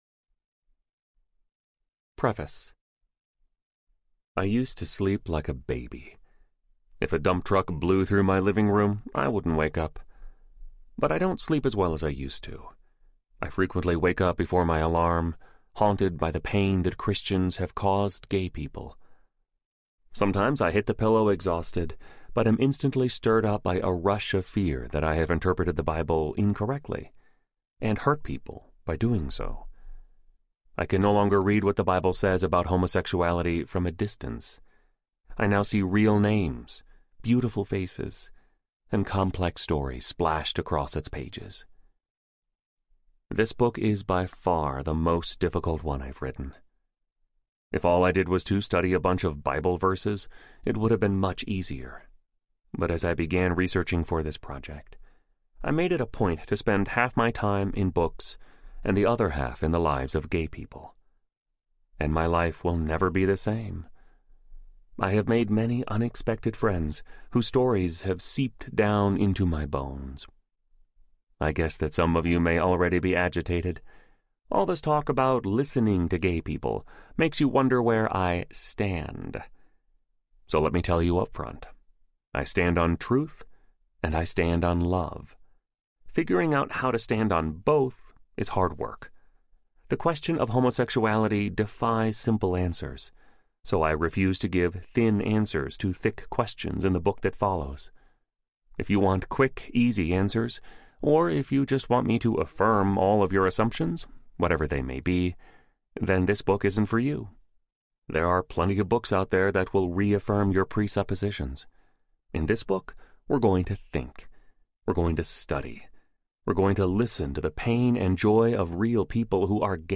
People to Be Loved Audiobook
Narrator